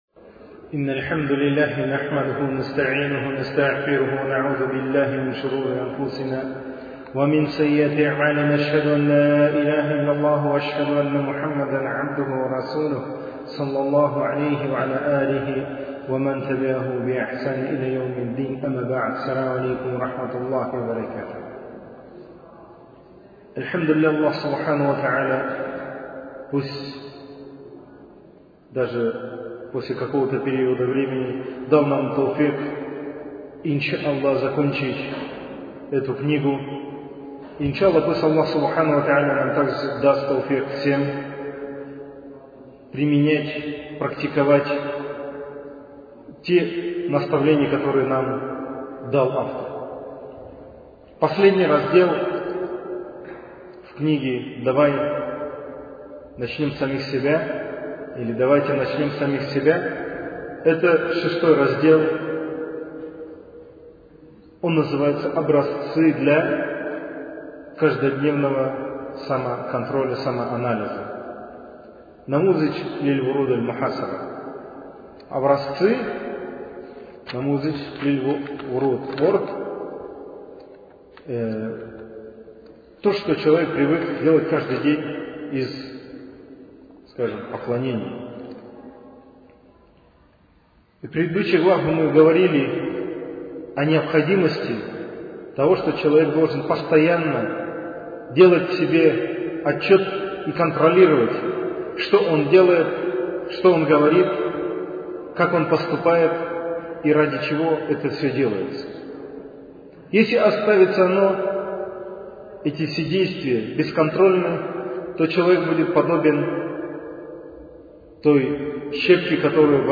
Цикл лекций